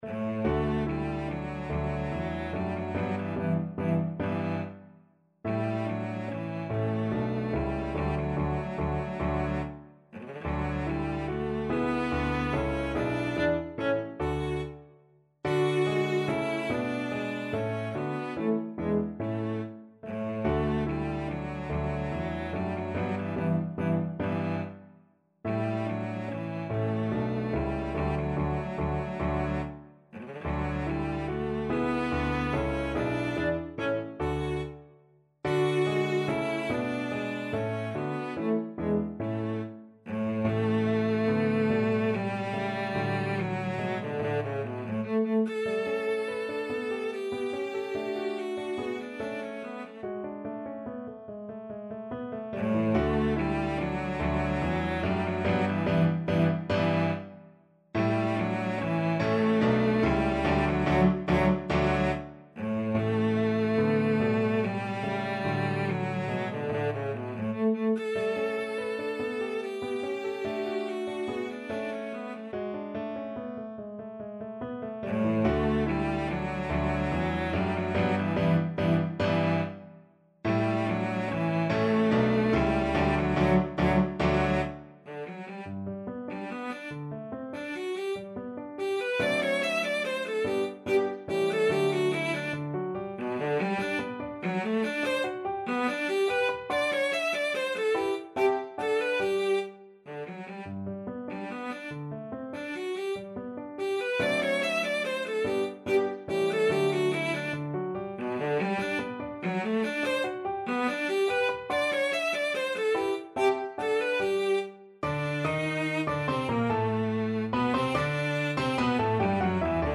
Cello
D major (Sounding Pitch) (View more D major Music for Cello )
Moderato =c.144
3/4 (View more 3/4 Music)
Classical (View more Classical Cello Music)